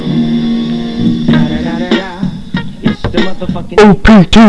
MIXES